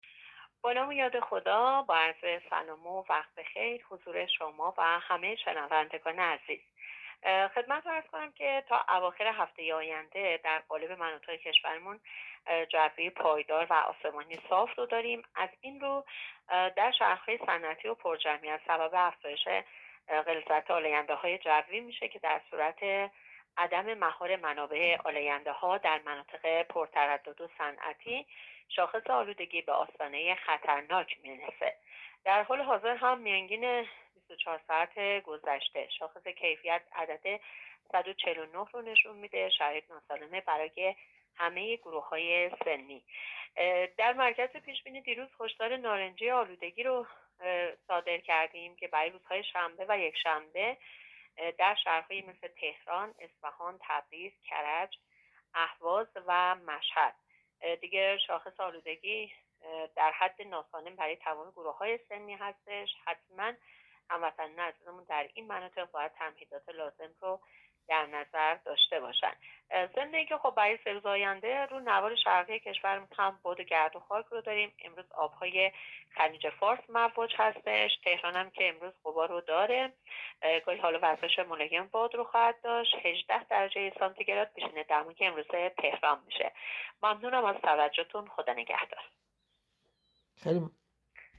گزارش رادیو اینترنتی پایگاه‌ خبری از آخرین وضعیت آب‌وهوای ۳۰ آبان؛